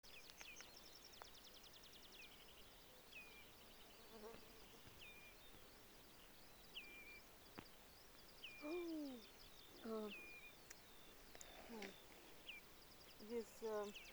Birds -> Waders ->
Common Redshank, Tringa totanus
Administratīvā teritorijaGulbenes novads
Notes/dzirdēti saucieni, ir ieraksts